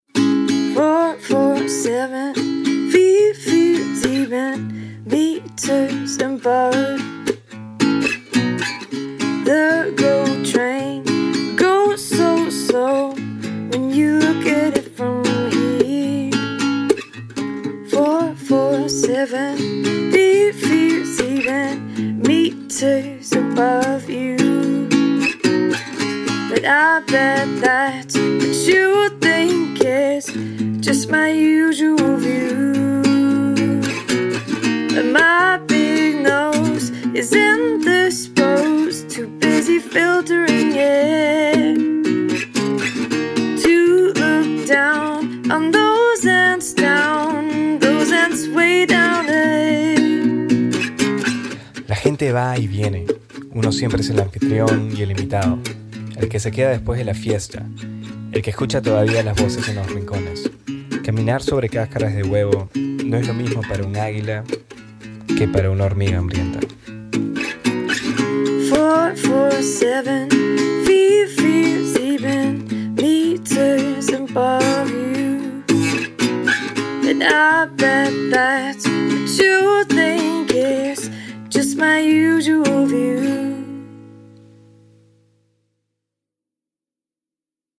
Guest spoken word